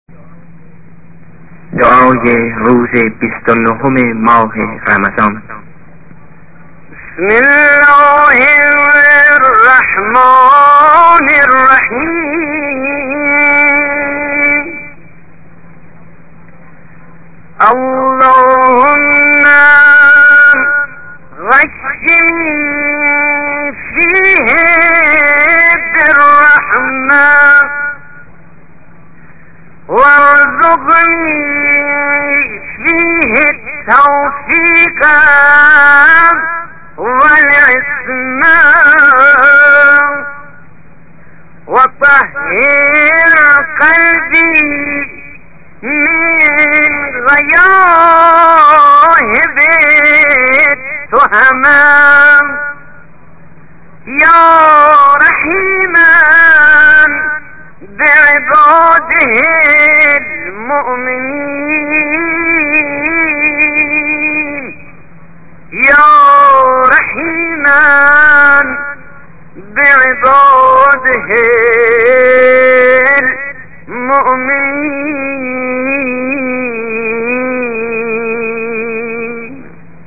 ادعية أيام شهر رمضان